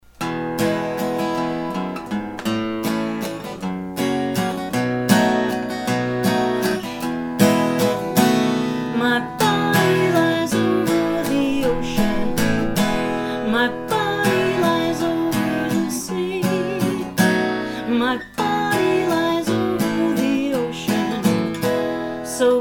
Traditional Children's Song Lyrics and Sound Clip